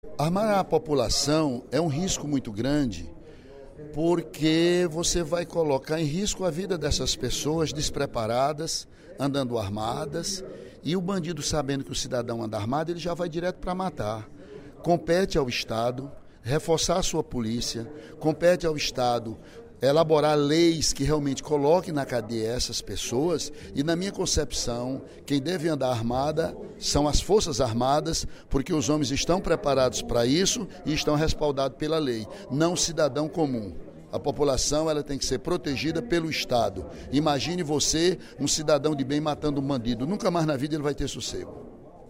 O deputado Ely Aguiar (PSDC) declarou, no primeiro expediente da sessão plenária desta sexta-feira (17/02), ser contra o armamento da população como forma de proteção e combate à violência.